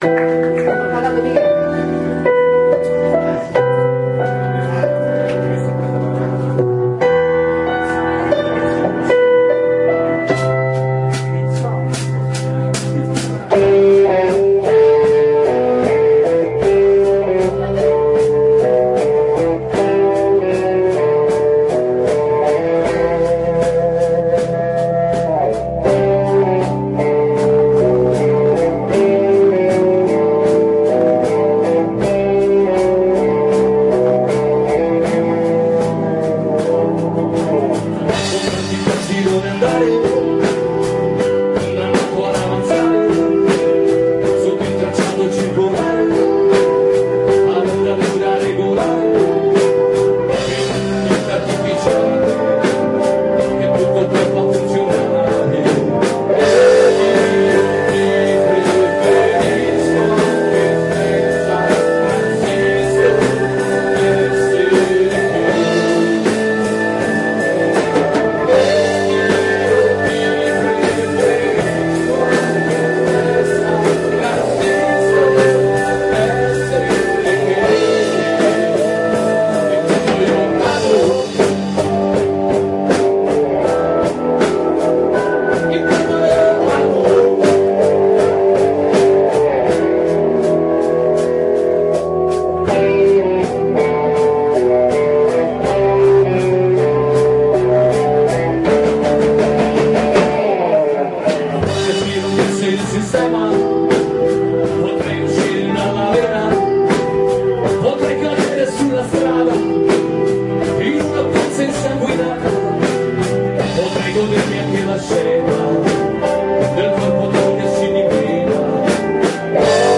Basso
Chitarra e Voce
Tastiere
Batteria